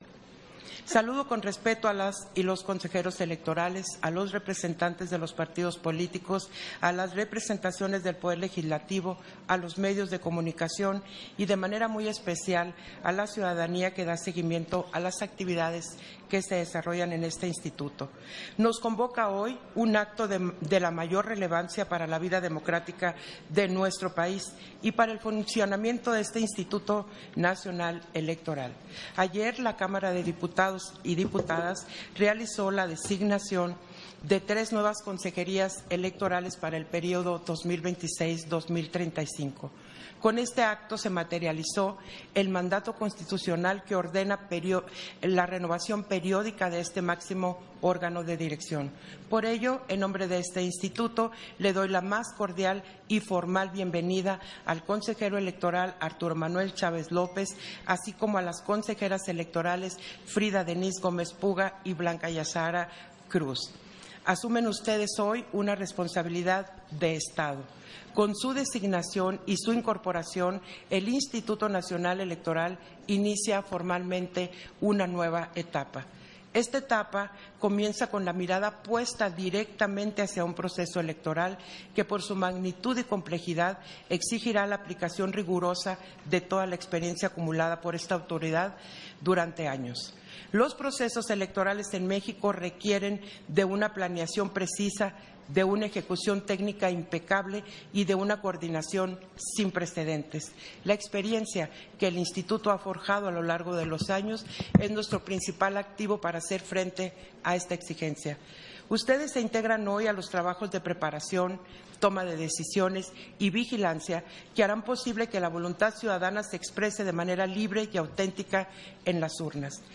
Intervención de la Consejera Presidenta, Guadalupe Taddei, en la toma de protesta de las consejerías electas para el periodo 2026-2035